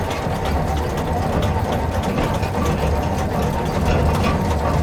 artillery-rotation-loop-2.ogg